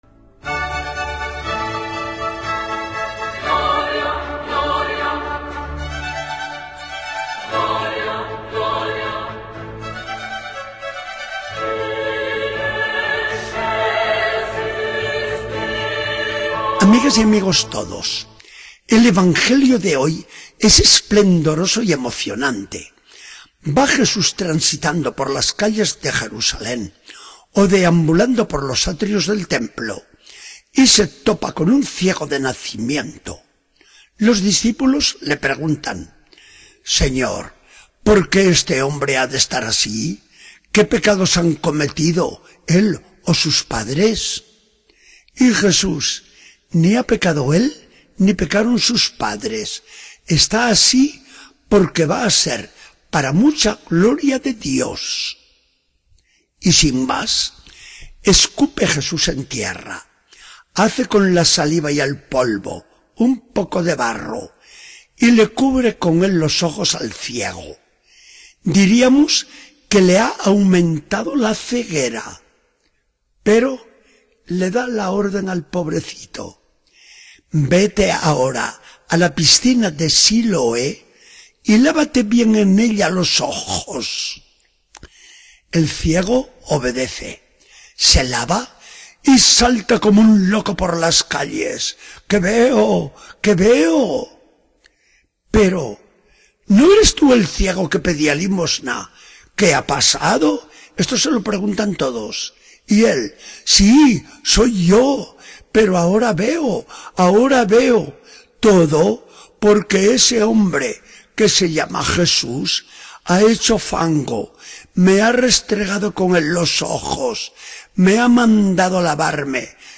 Charla del día 30 de marzo de 2014.